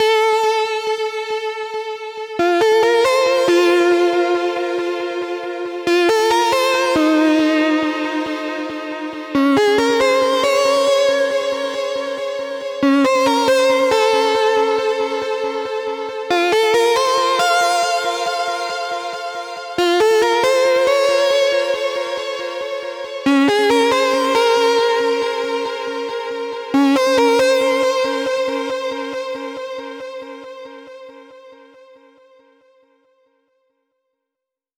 VTS1 Another Day Kit 138BPM Mainlead.wav